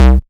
• Dry Kick Drum C Key 152.wav
Royality free kickdrum tuned to the C note. Loudest frequency: 240Hz
dry-kick-drum-c-key-152-FbX.wav